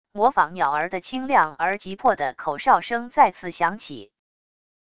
模仿鸟儿的清亮而急迫的口哨声再次响起wav音频